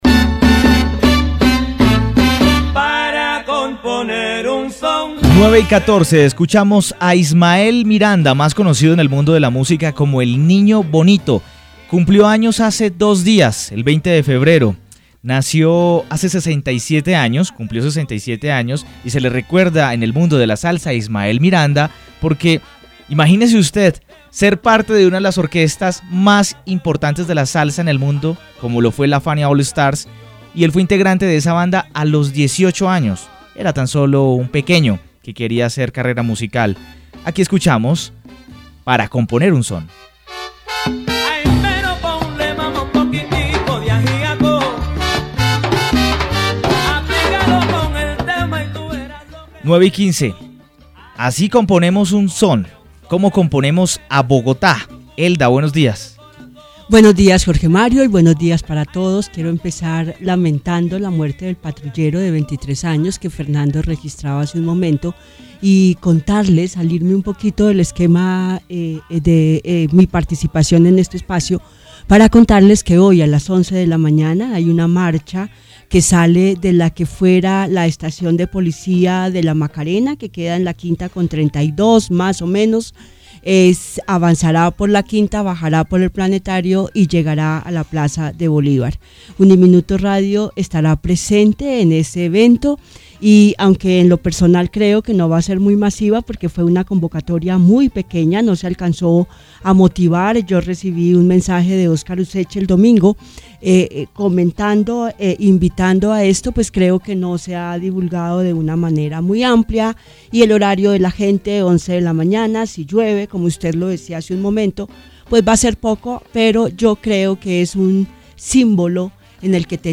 Inicia la columna, como antecedente a una serie de positivos y negativos, que a su vez inspiraron un interesante diálogo en la cabina de Uniminuto Radio 1430 AM, en el espacio